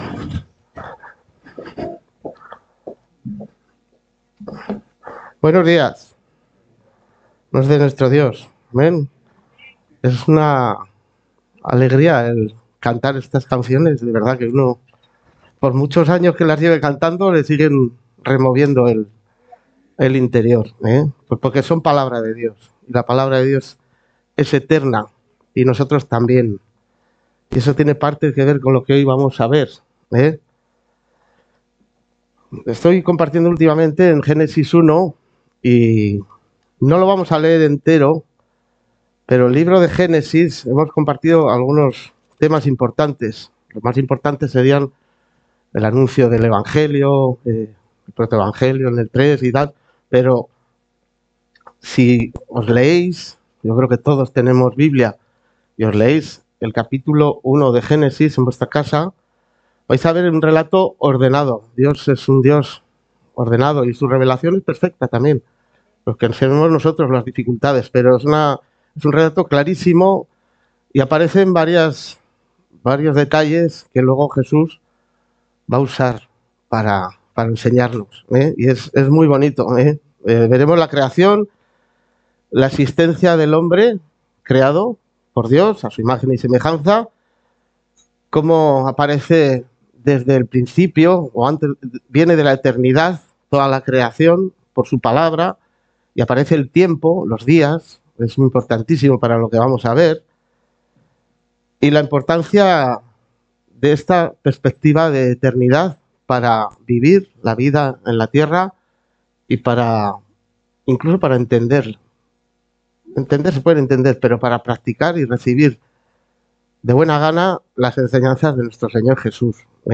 Predicación